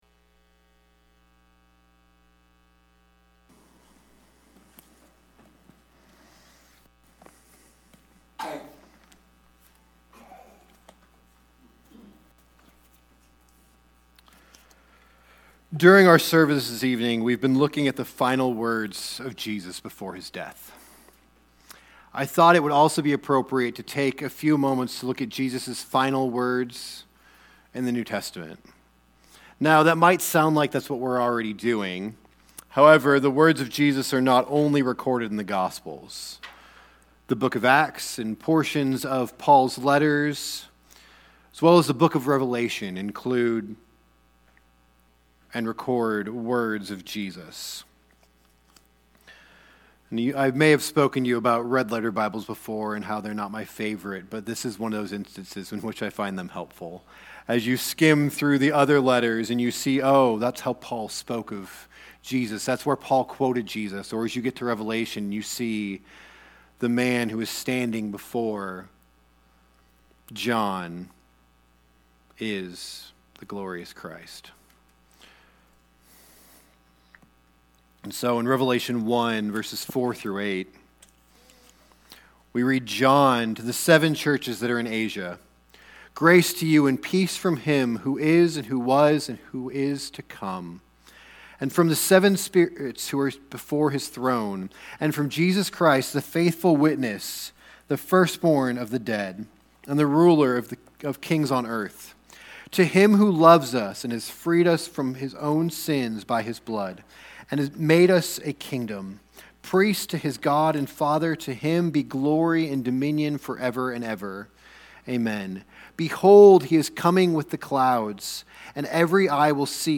Sermons | Maranatha Baptist Church
This is from our 2025 Good Friday service at Maranatha Baptist Church in Globe, Arizona.